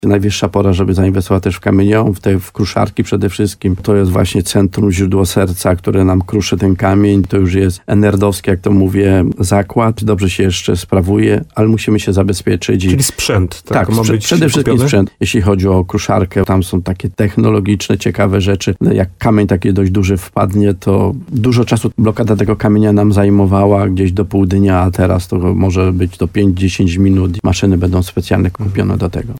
Na przykład, jak wpadnie jakiś duży kamień, to była nawet blokada przecz pół dnia, a teraz będą specjalne maszyny do tego i ta blokada może trwać najwyżej do 5 do 10 minut – podkreśla wójt gminy Chełmiec.